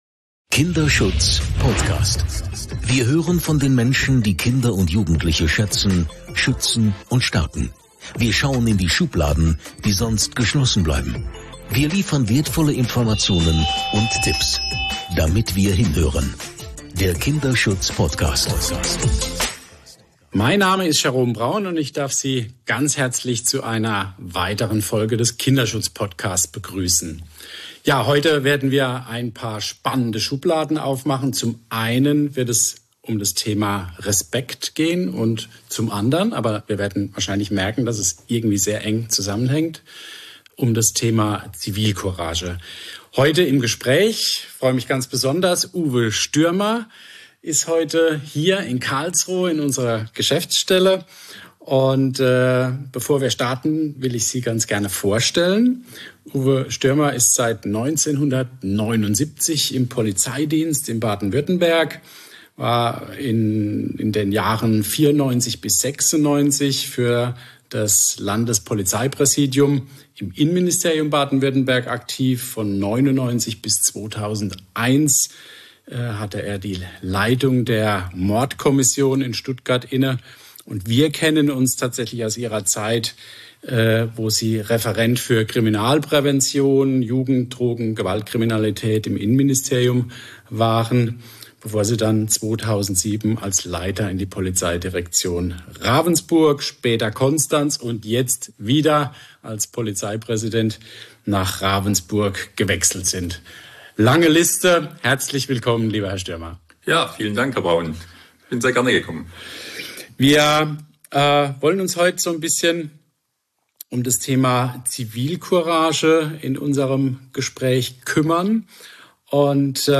Im nächsten Atemzug zeigt das Gespräch auch die Leuchttürme und sehr wichtigen Beispiele von Bürger:innen die Zivilcourage zeigen.